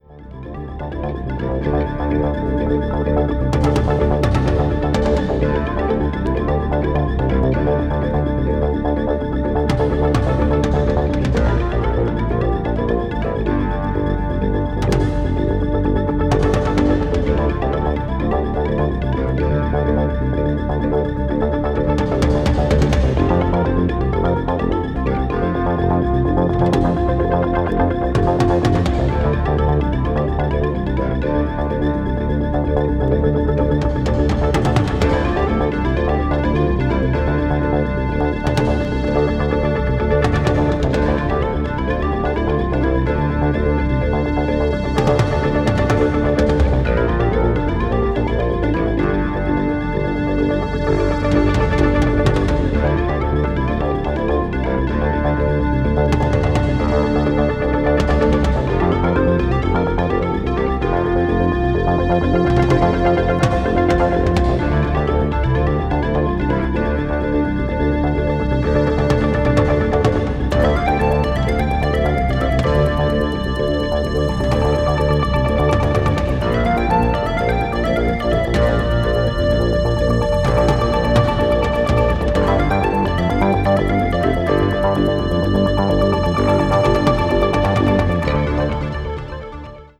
electronic   progressive rock   symphonic rock   synthesizer